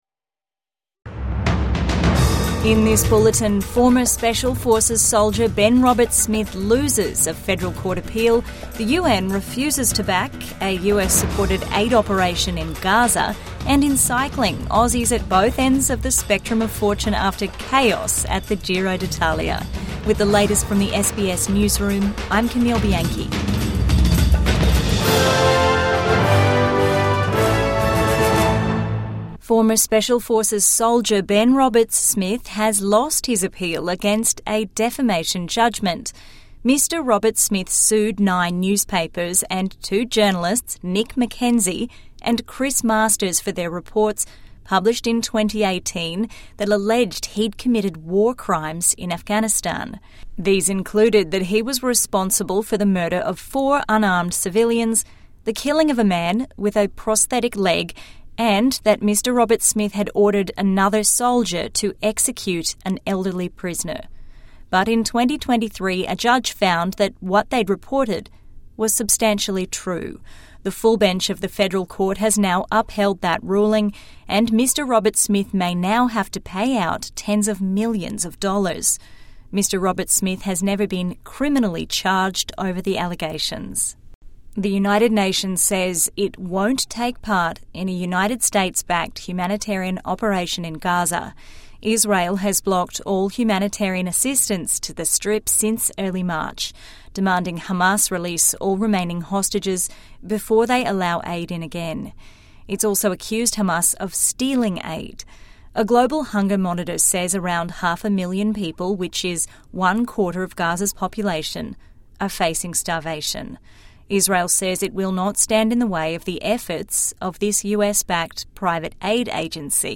Ben Roberts-Smith loses his Federal Court appeal | Midday News Bulletin 16 May 2025